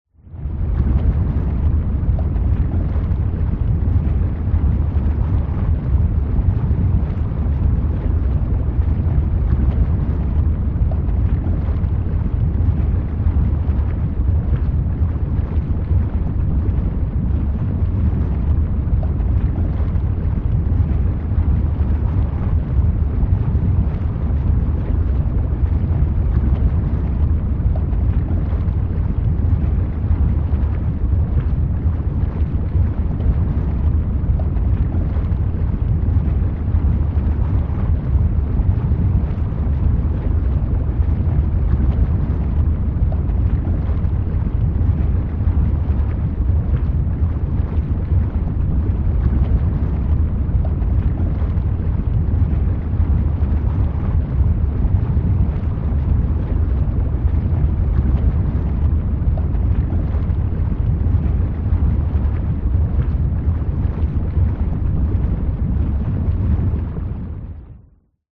Звуки спа, атмосфера
Спа с джакузи, подводный вид, пузырьки в воде